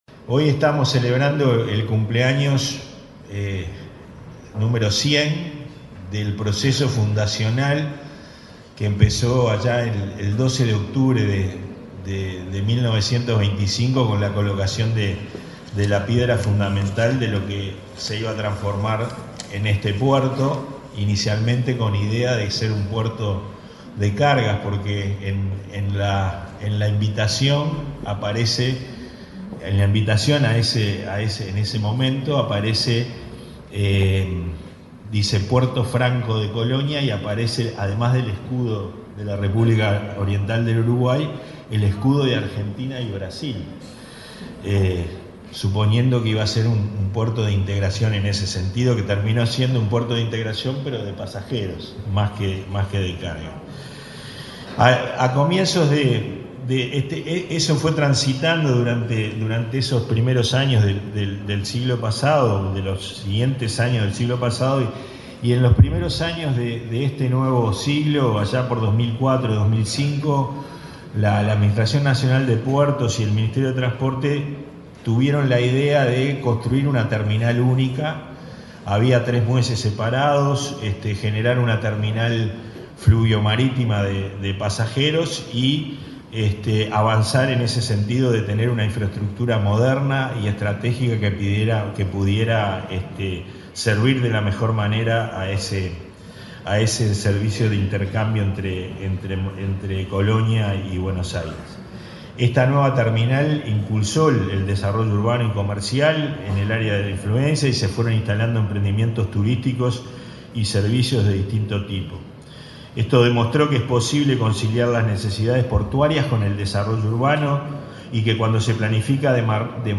Palabras de autoridades en celebración por los 100 años del puerto de Colonia
Palabras de autoridades en celebración por los 100 años del puerto de Colonia 30/10/2025 Compartir Facebook X Copiar enlace WhatsApp LinkedIn El presidente de la Administración Nacional de Puertos, Pablo Genta; el ministro de Turismo, Pablo Menoni, y su par de Transporte, Lucía Etcheverry, participaron en la ceremonia conmemorativa de los 100 años del puerto de Colonia.